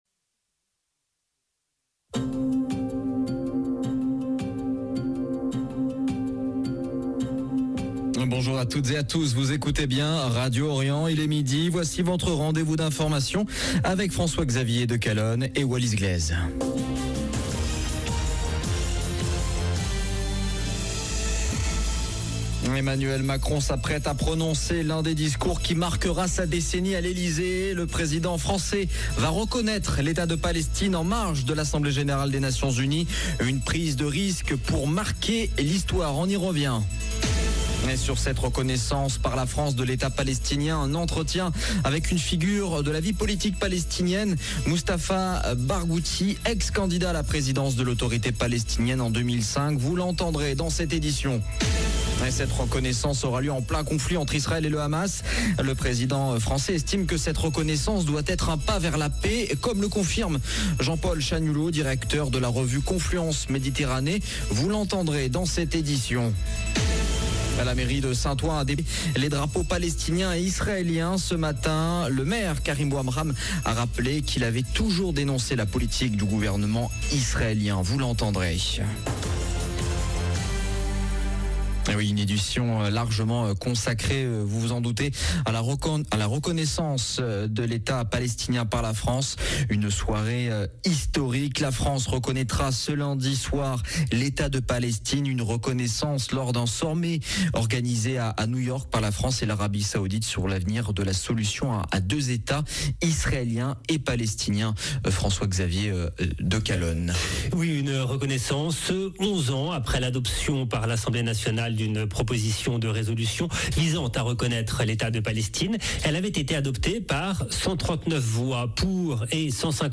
Journal de midi du 22 septembre 2025
Un entretien avec une figure de la vie politique palestinienne, Mustafa Barghouthi, ancien candidat à la présidence de l’Autorité palestinienne en 2005.